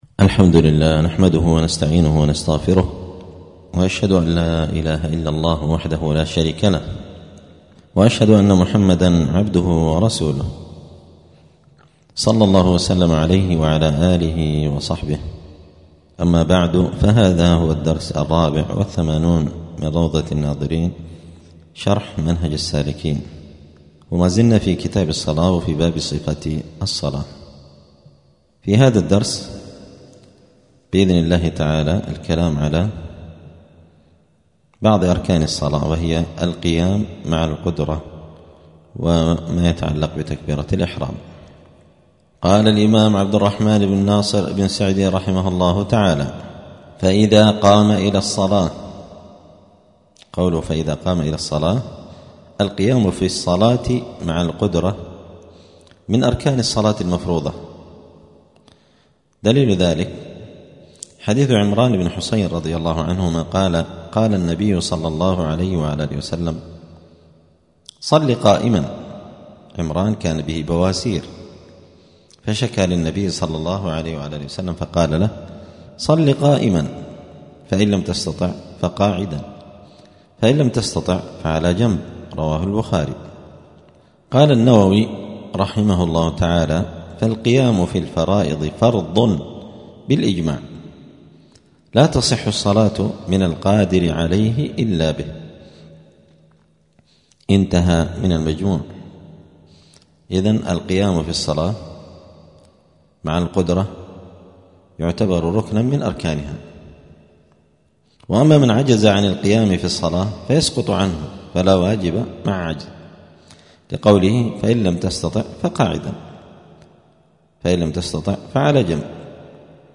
*الدرس الرابع والثمانون (84) {كتاب الصلاة باب صفة الصلاة من أركان الصلاة القيام مع القدرة وتكبير الإحرام}*
دار الحديث السلفية بمسجد الفرقان قشن المهرة اليمن